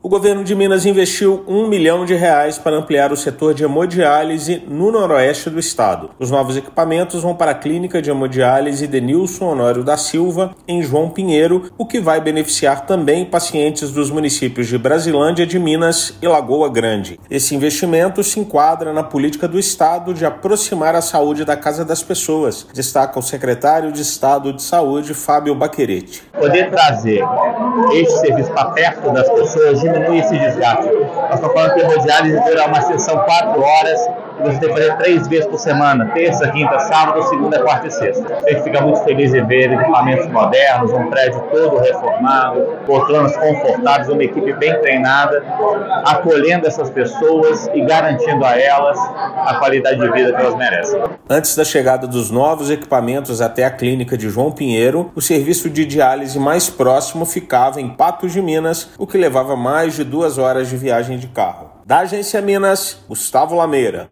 Expectativa é que a unidade atenda mais de 70 mil habitantes na região e pacientes tenham serviço mais perto de casa. Ouça matéria de rádio.